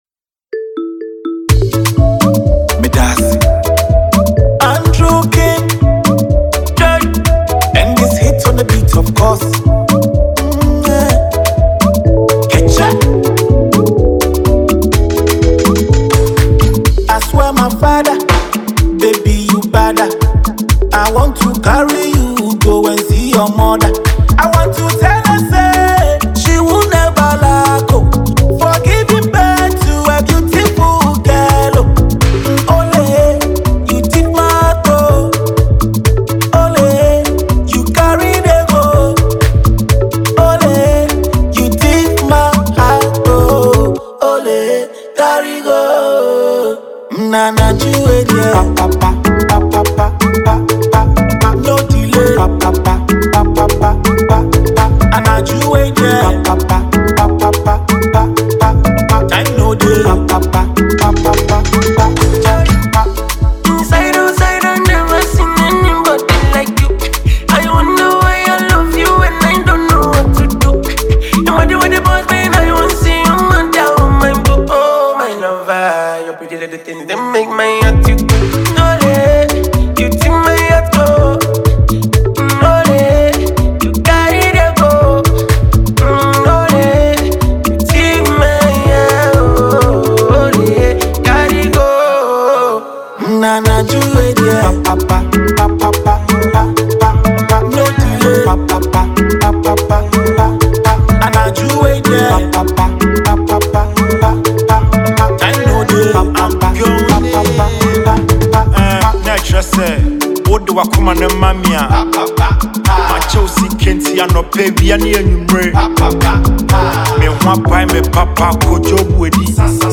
Enjoy this amazing Afrobeat production.